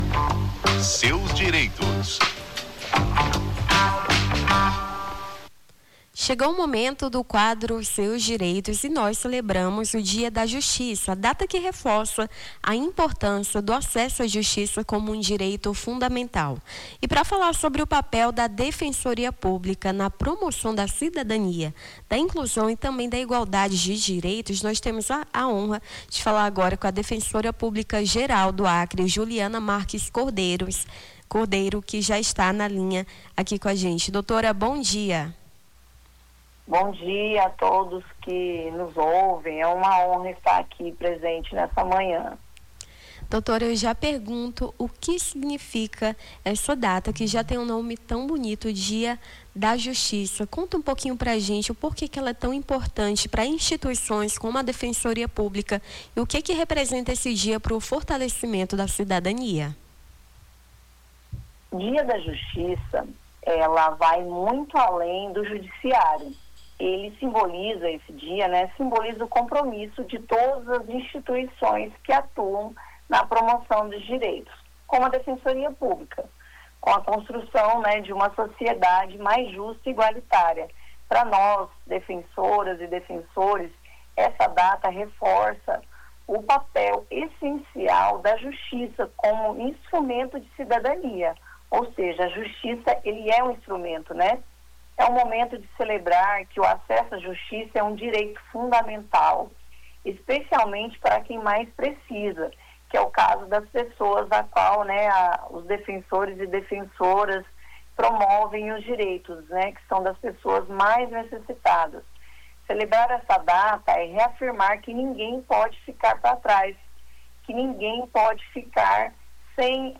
Na segunda-feira, dia 8, entrevistamos a defensora pública-geral do Acre, Juliana Marques Cordeiro, que destacou o Dia da Justiça como uma data dedicada a reforçar a relevância do acesso à justiça enquanto direito fundamental.